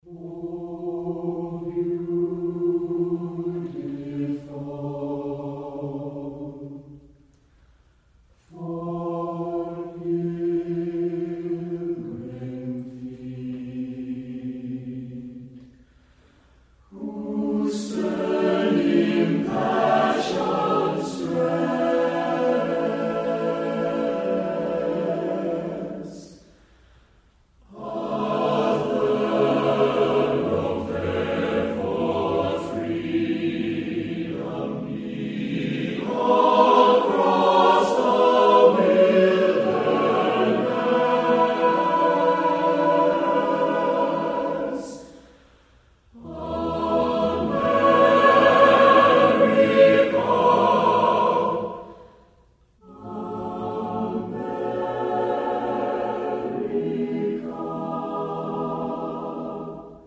Performers: U.S. Air Force Singing Sergeants